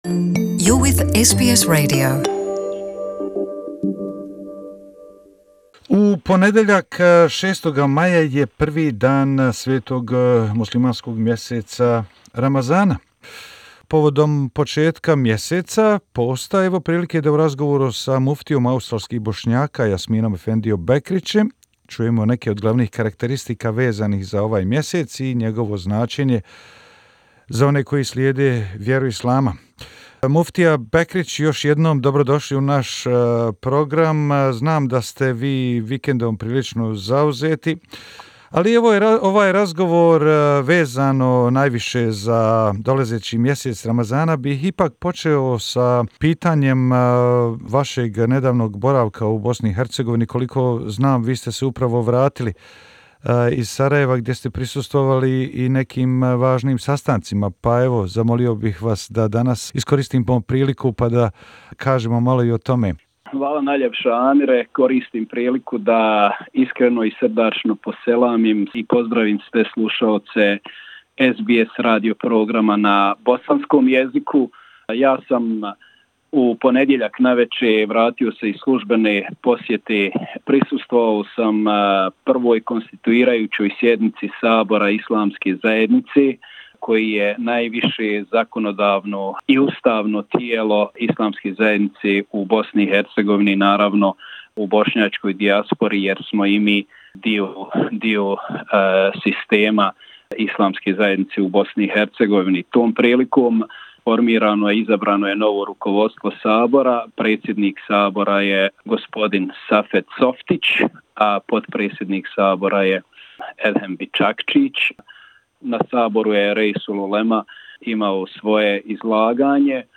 Imam Jasmin Bekric, Interview